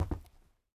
tbd-station-14/Resources/Audio/Effects/Footsteps/floor2.ogg